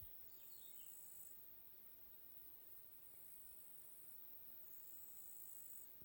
Zwitscherschrecke (Tettigonia cantans (Fuessli, 1775)) Zschonerrgund Dresden, Juni 2023